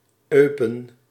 Eupen (German: [ˈɔʏpn̩] , French: [øpɛn] , Dutch: [ˈøːpə(n)]
Nl-Eupen.ogg.mp3